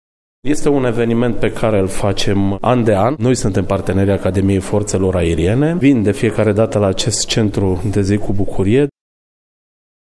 Acțiunea a fost organizată împreună cu Primăria municipiului Brașov. Viceprimarul Costel Mihai: